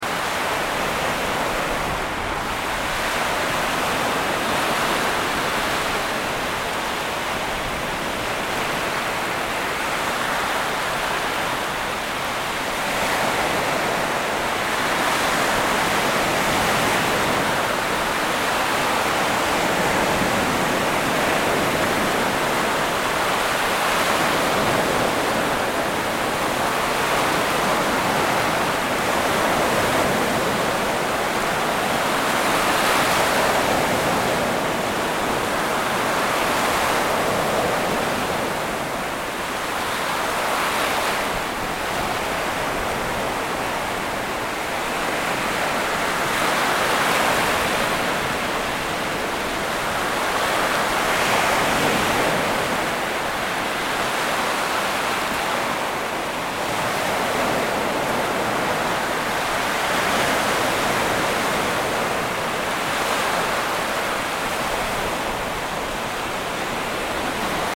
Nature Sounds / Sound Effects / Water Sounds
Sea-waves-sound-effect-relaxing-ocean-wave-noise-for-background-or-meditation.mp3